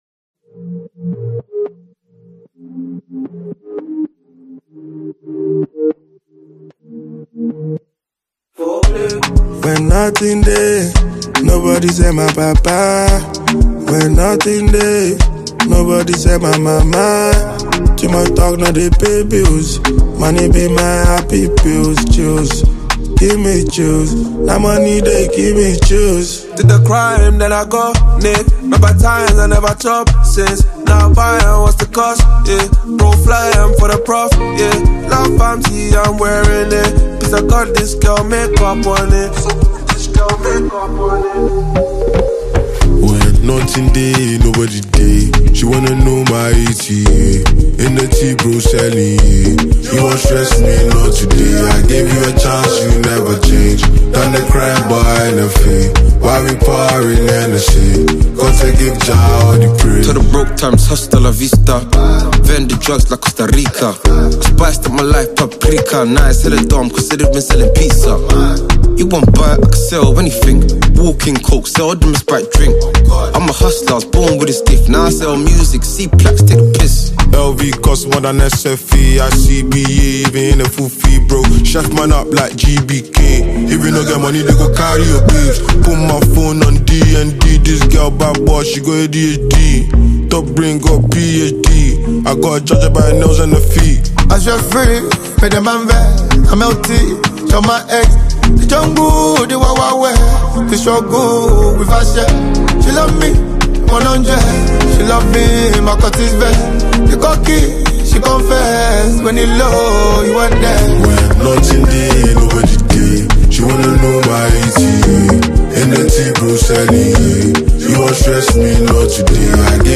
Afroswing
Nigerian rapper